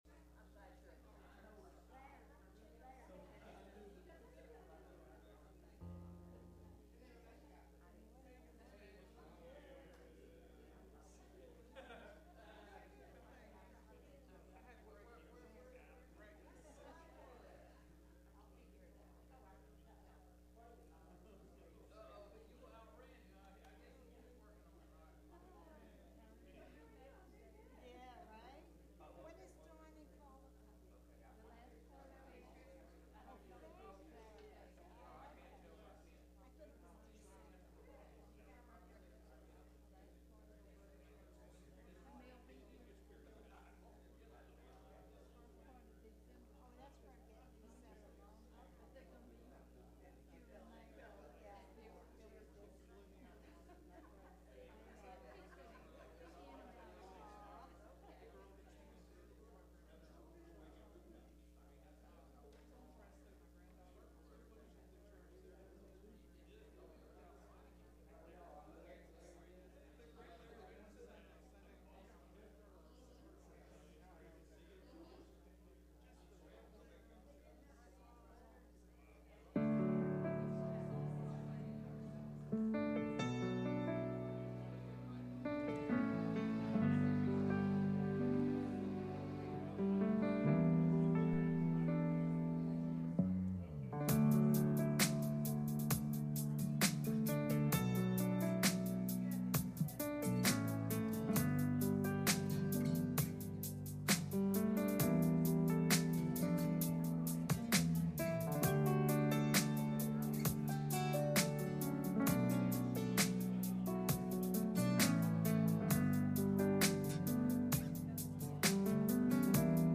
Sunday Morning Service
Service Type: Sunday Morning